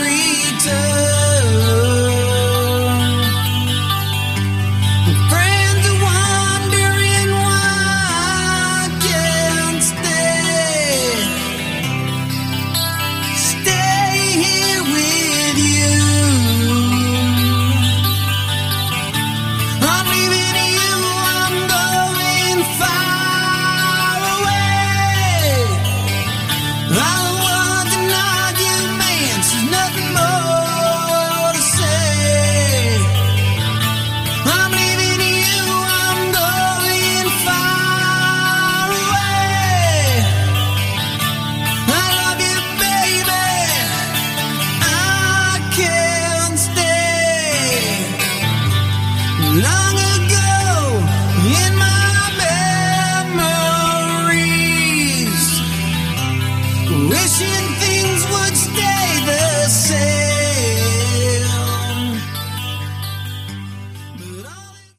Category: Hard Rock
vocals
lead guitar
rhythm guitar
bass
drums
Acoustic ballad
Good sleazy hard rock.